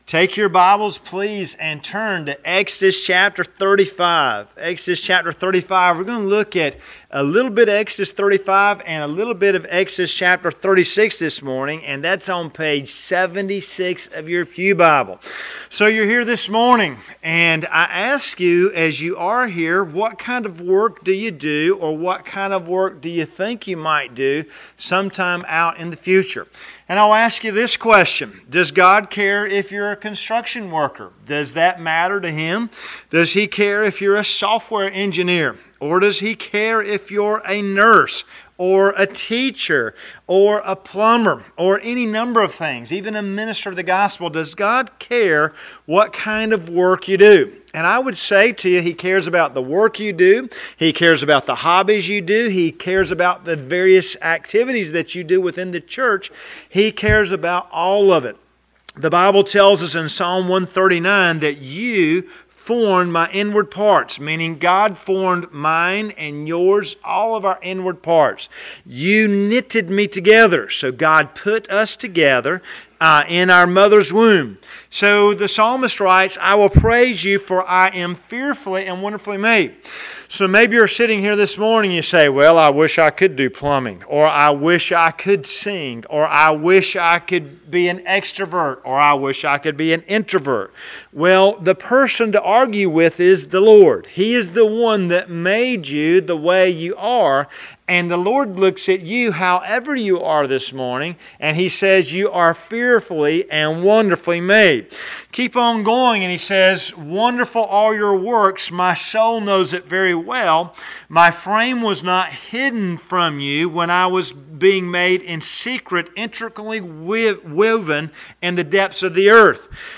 Sermons - Tippett's Chapel Free Will Baptist Church
Sunday AM Service